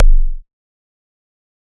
EDM Kick 46.wav